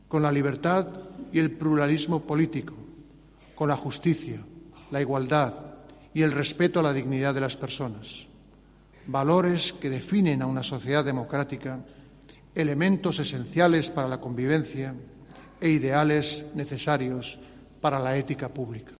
Felipe VI ha apelado a ese respeto en su intervención en la entrega de despachos a la 68 promoción de la carrera judicial, donde ha estado acompañado por la ministra de Justicia, Dolores Delgado; el de Interior, Fernando Grande Marlaska; el presidente del Consejo General del Poder Judicial (CGPJ), Carlos Lesmes; y la Fiscal General del Estado, María José Segarra.